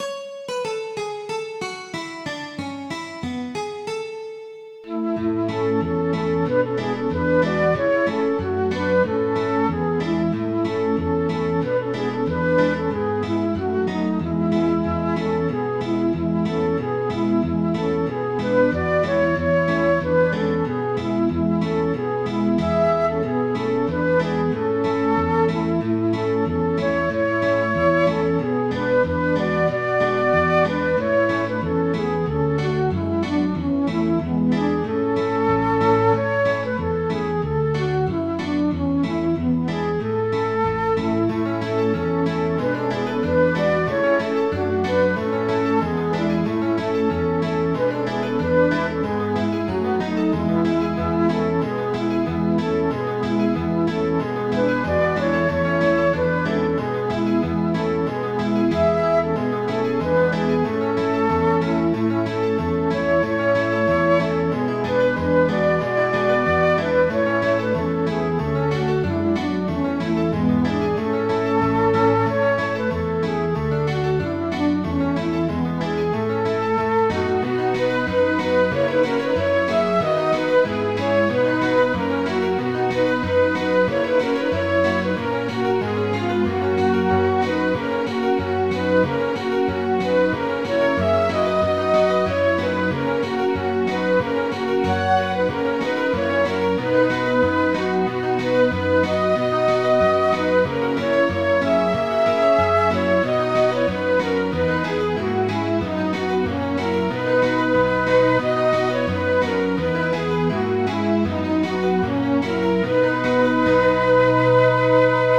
Midi File, Lyrics and Information to The Lass of Richmond Hill